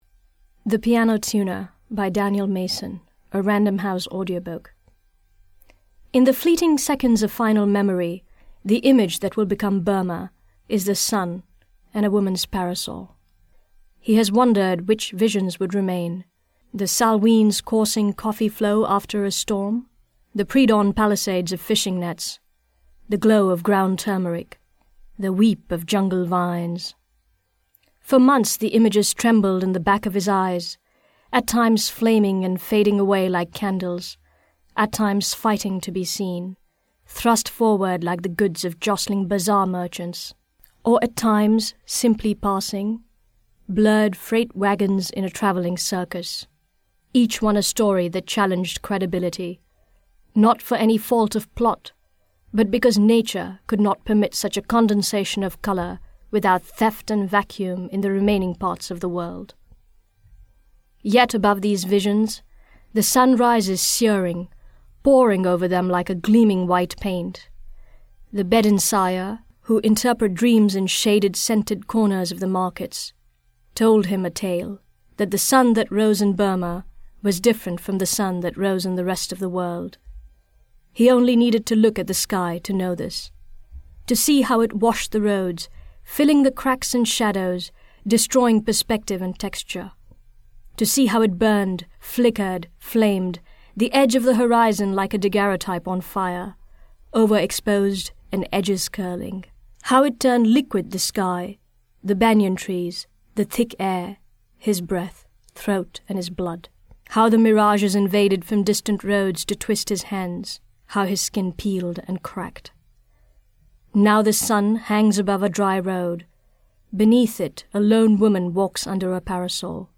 Educated, intelligent voice ideal for business or legal commentary, technical speaking, scientific jargon and literature as well as philosophy.
Sprechprobe: Industrie (Muttersprache):